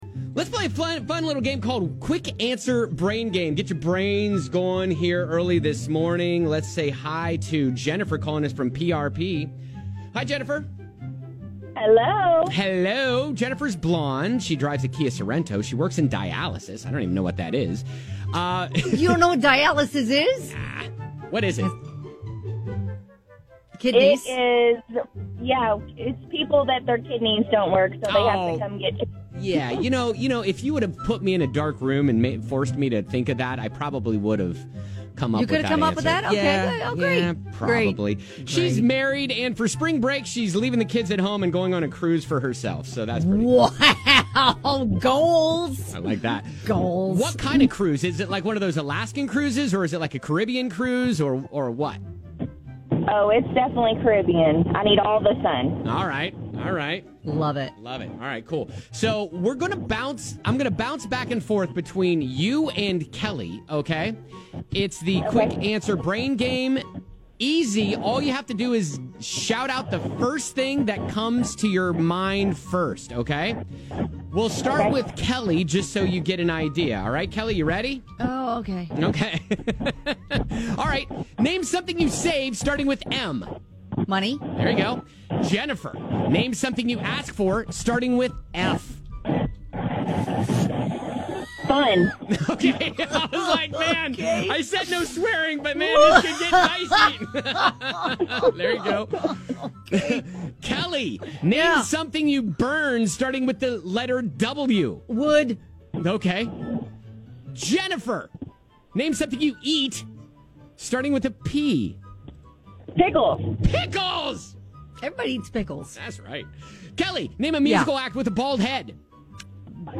shouted out the 1st answer that came to mind in the Quick Answer Brain Game!